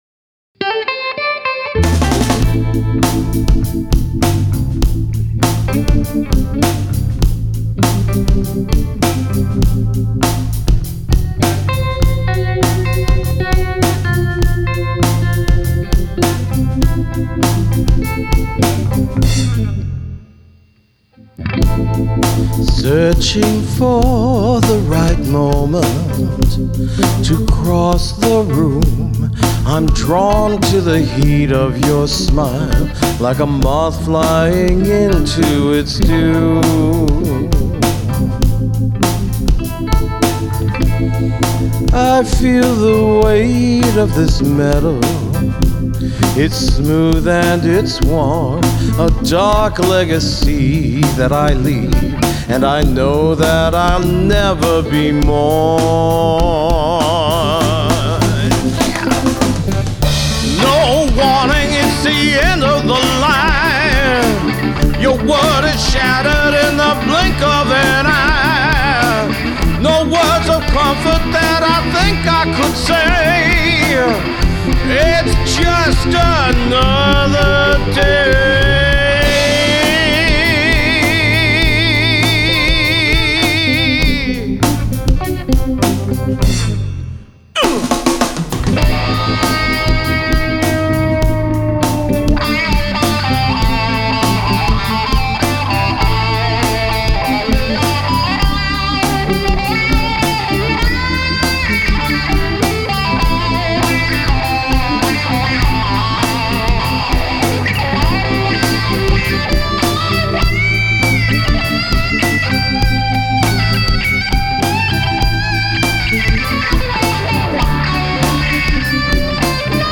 • Amp: DV Mark Little 40 Head into an open-back Avatar 1 X 12 with a Jensen P12N speaker
• Rhythm Guitar: Slash L Katie May into a Voodoo Labs Micro Vibe
• Lead Guitar: ’59 Les Paul Replica into a Vox Big Bad Wah
• Bass: Squier “P” Bass